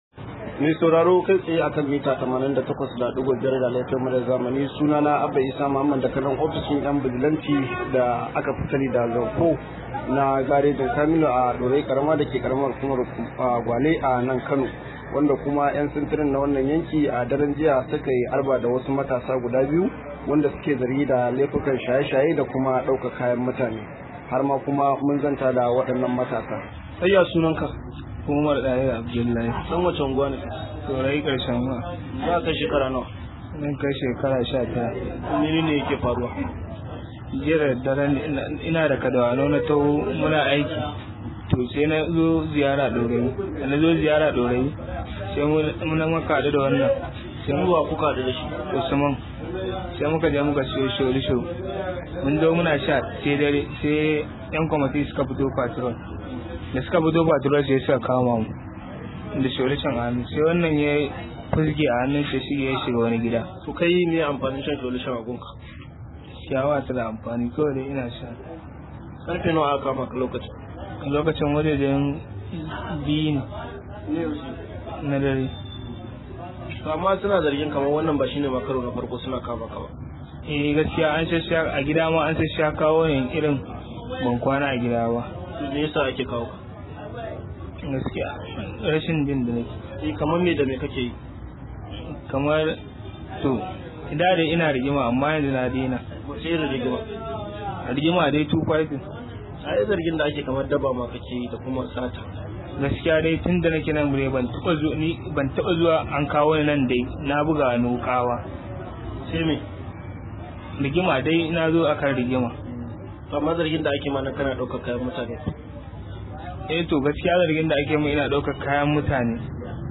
Rahoto: ‘Yan bijilante sun kama matasa 2 da zargin sace-sace a Dorayi